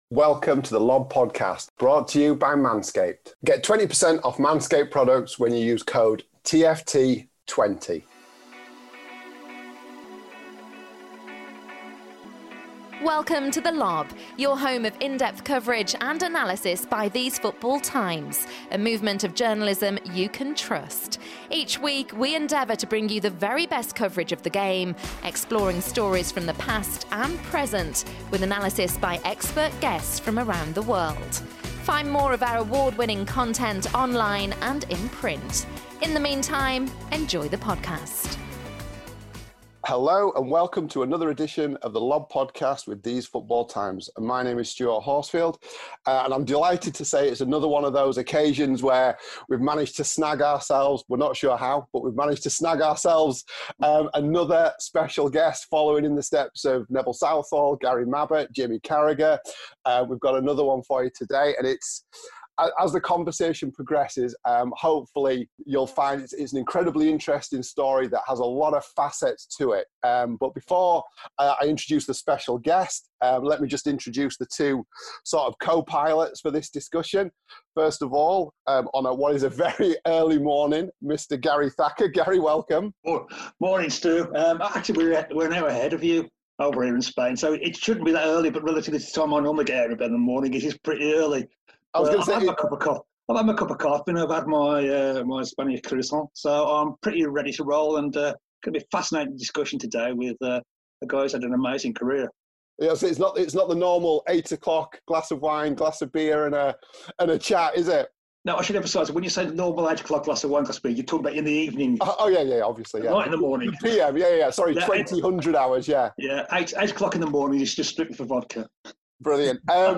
A chat with Luke Chadwick